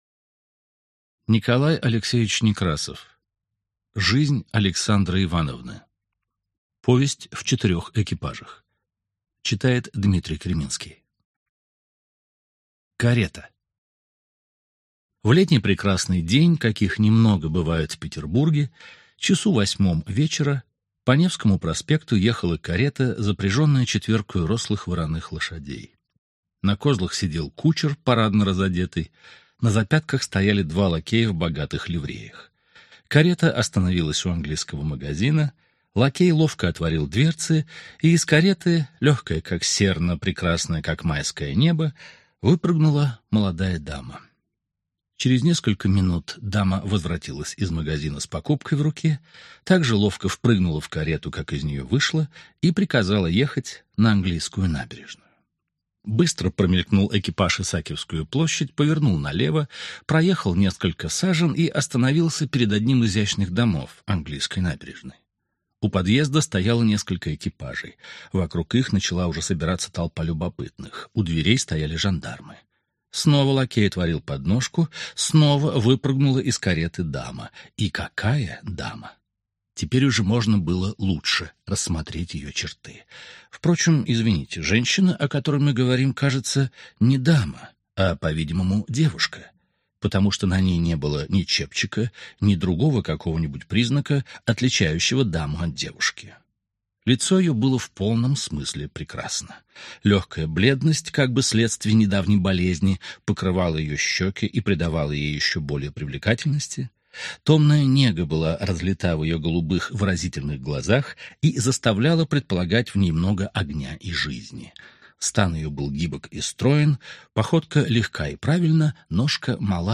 Аудиокнига Жизнь Александры Ивановны | Библиотека аудиокниг